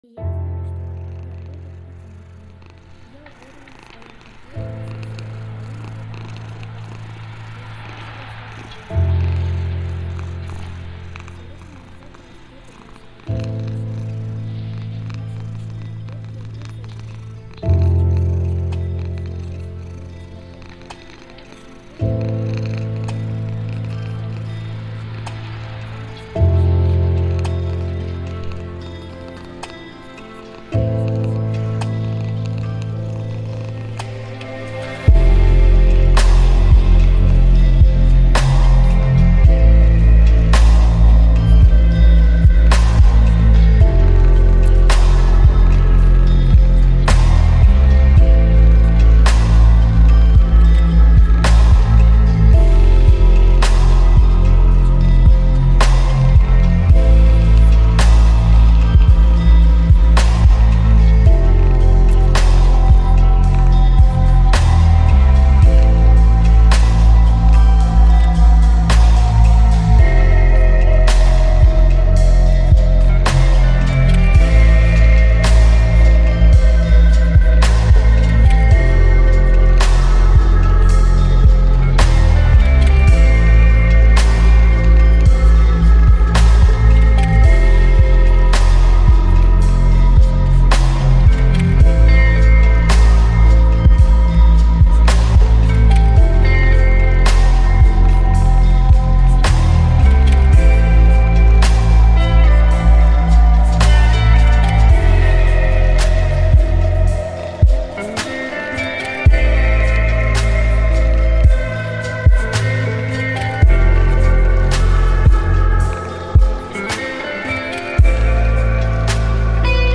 Звуки невесты - скачать и слушать онлайн бесплатно в mp3
На этой странице собраны звуки, связанные с невестой: шелест платья, звон бокалов, трогательные клятвы.